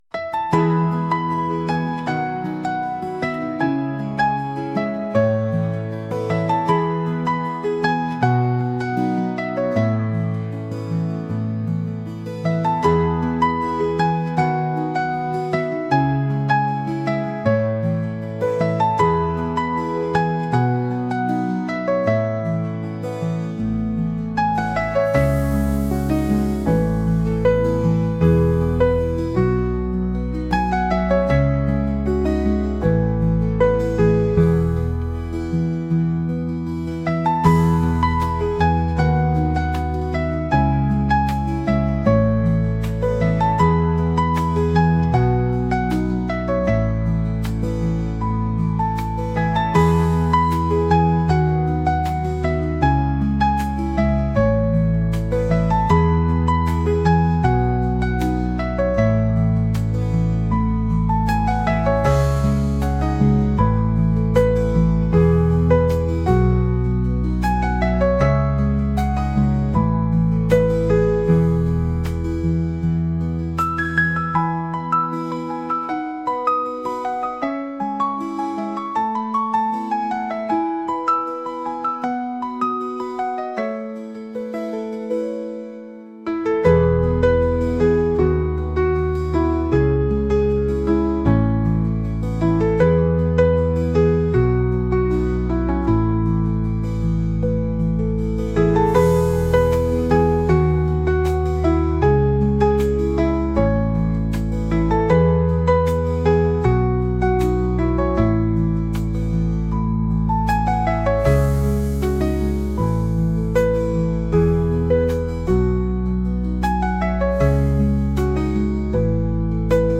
「おしゃれ」